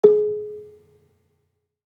Gambang-G#3-f.wav